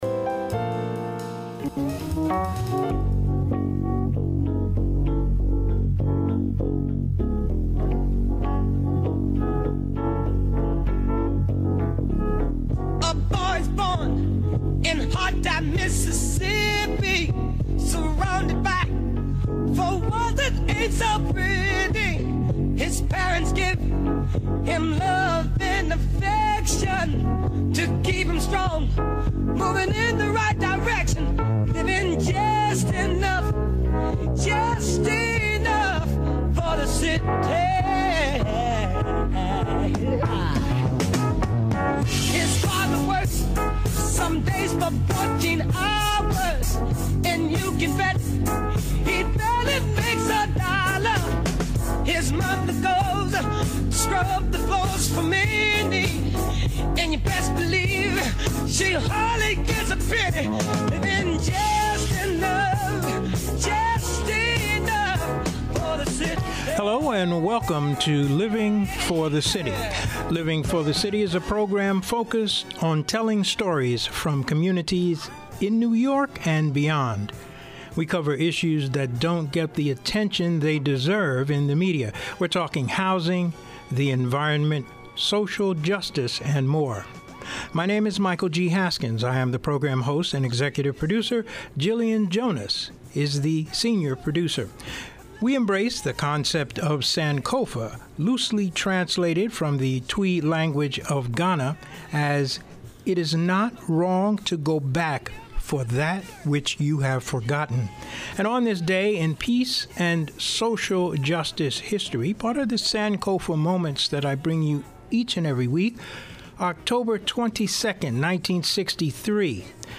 During the interview